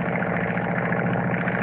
bomber2.ogg